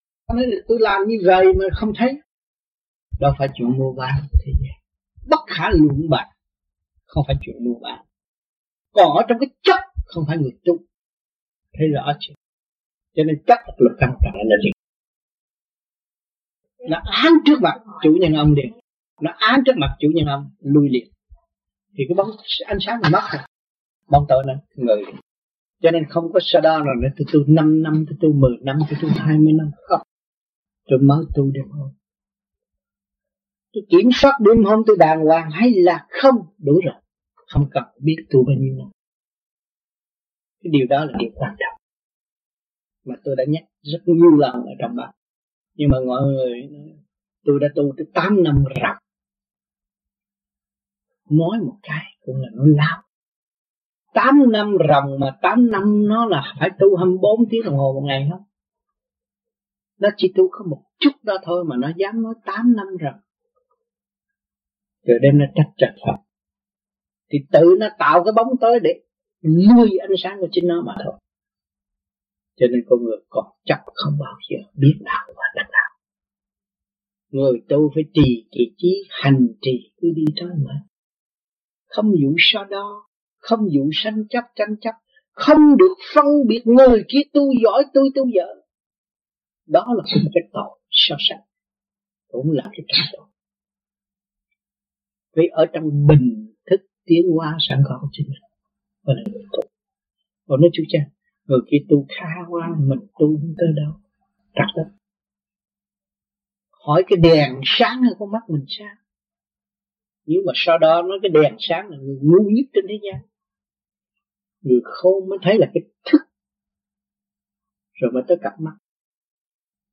VẤN ĐẠO